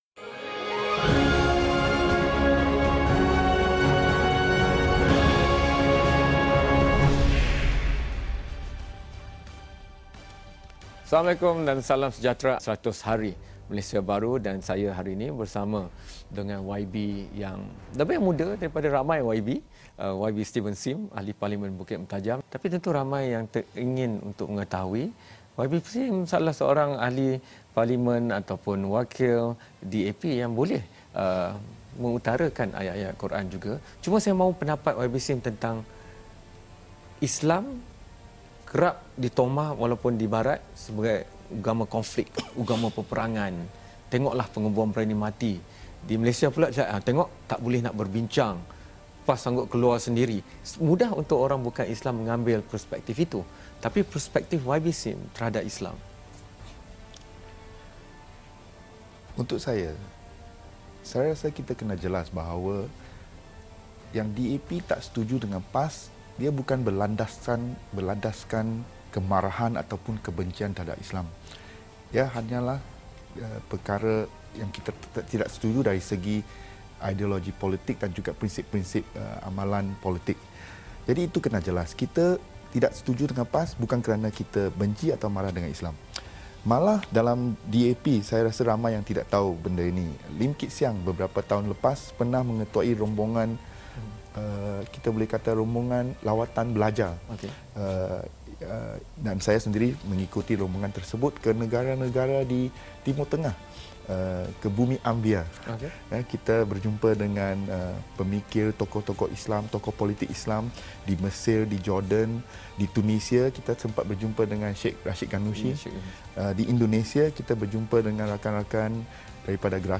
YB Steven Sim, Ahli Parlimen Bukit Mertajam bercakap berkenaan punca keretakan hubungan parti PAS dan DAP serta aspirasi anak muda.